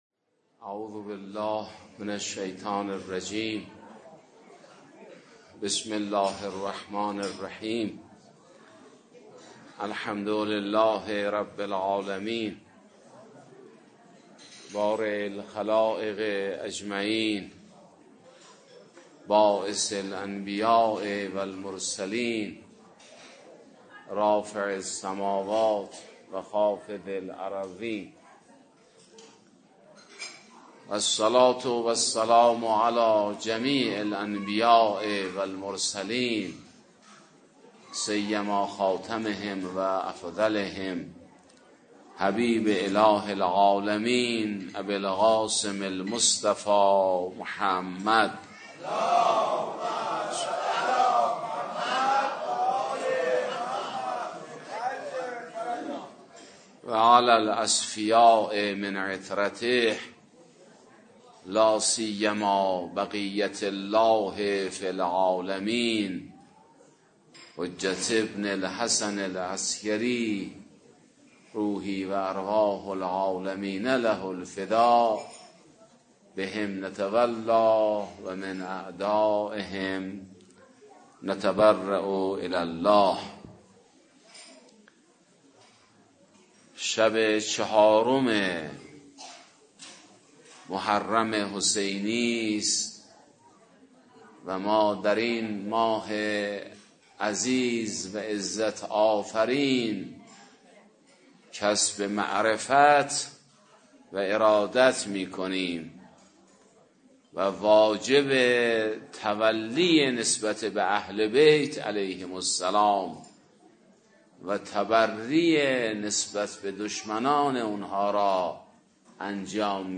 چهارمین شب از مراسم عزاداری حضرت اباعبدالله الحسین(ع) با حضور پرشور مؤمنان و دلدادگان اهل‌بیت(ع)
در پایان این جلسه، مصیبت شهادت حر بن یزید ریاحی و فرزند حضرت زینب(س) قرائت شد و حاضران با ذکر مصیبت و مرثیه‌خوانی، یاد شهدای کربلا و غربت اهل‌بیت(ع) را گرامی داشتند.